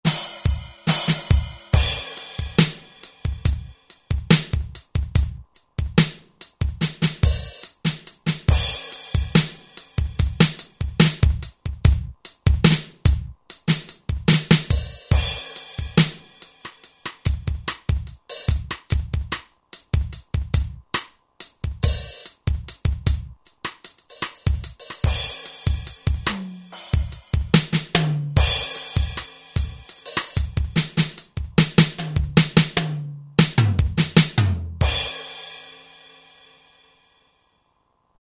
Roland S-760 audio demos
5 S-760DRUMSET.mp3